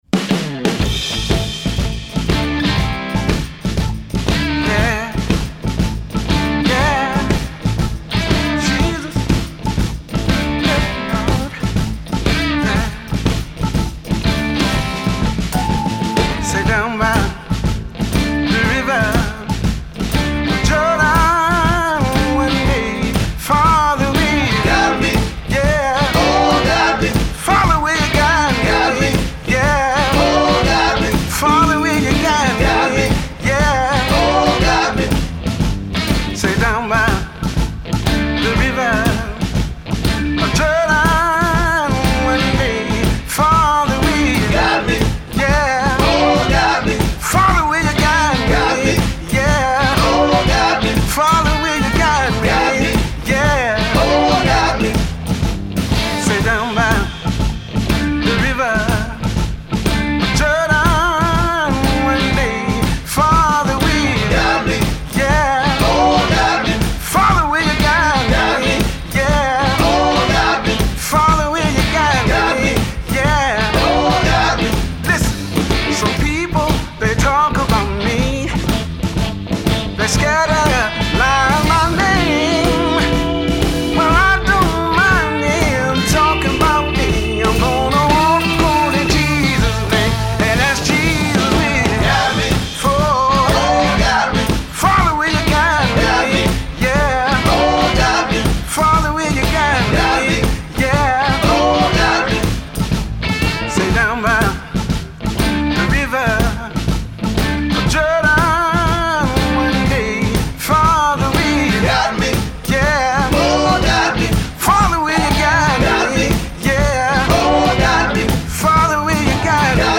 Un disque à l’ancienne à la gloire de Dieu et du groove….